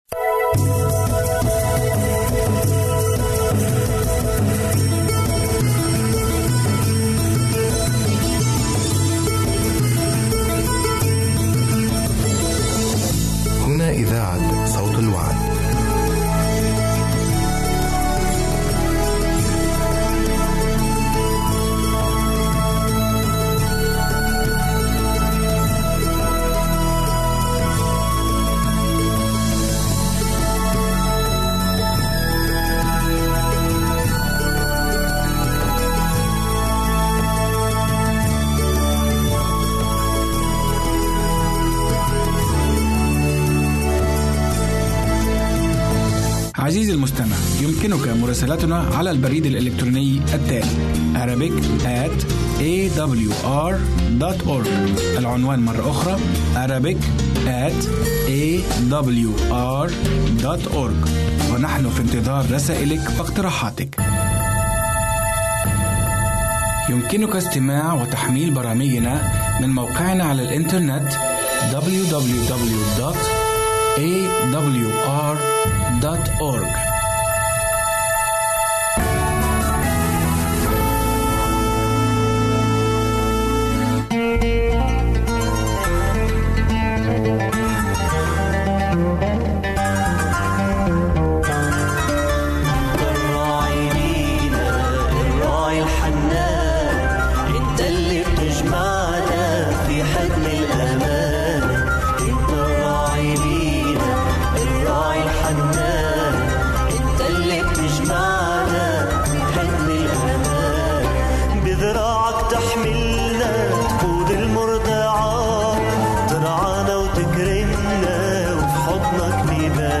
برنامج إذاعي يومي باللغة العربية AWR يتضمن برامج مقابلة ، حياة عائلية ، جولة مع أمثال المسيح.